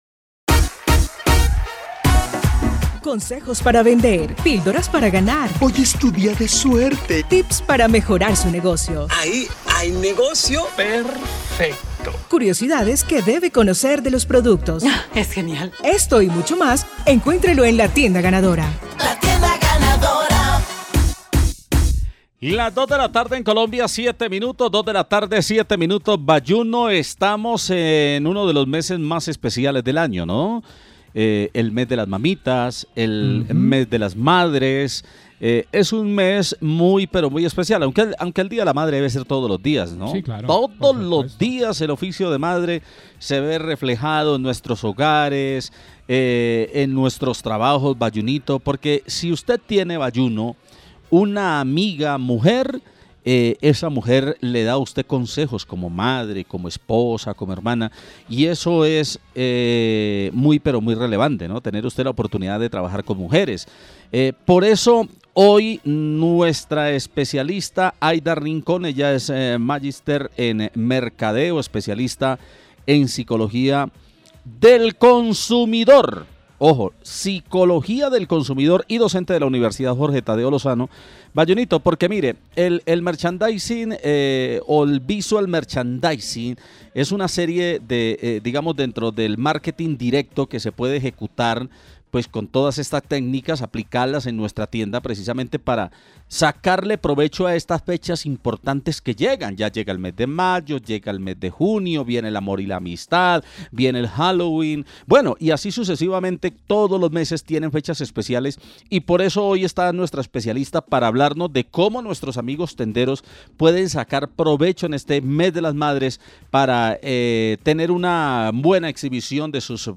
entrevista_mes_madre_merchandising.mp3